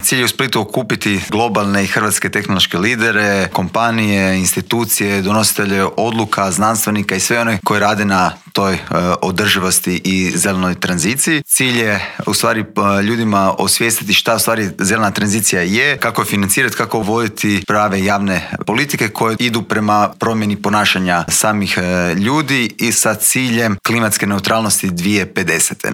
ZAGREB - Hrvatska nedvojbeno ima potencijala za stvaranje "zelene" i energetski održive budućnosti, a na nama je da ih uspješno iskoristimo.